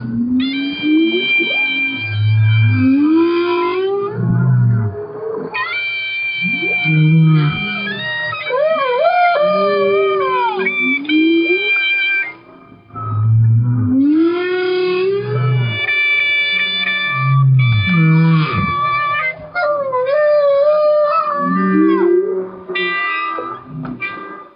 Live Improvisation in Maui